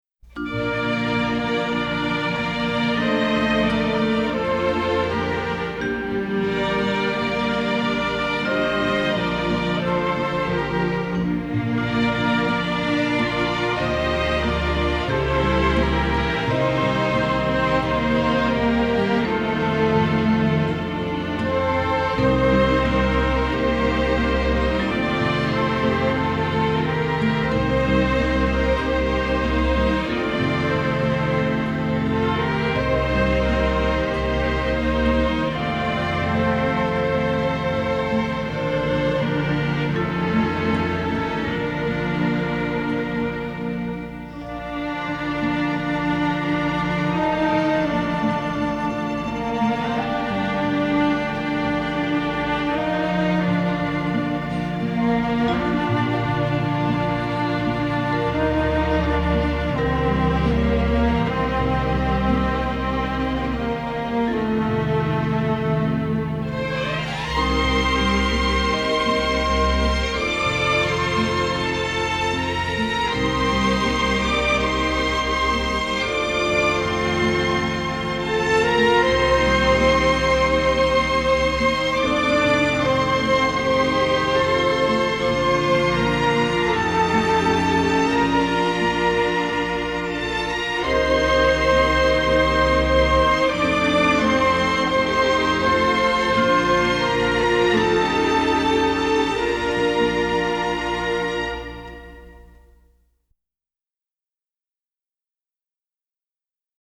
Жанр: Soundtrack, Folk, World, & Country, Stage & Screen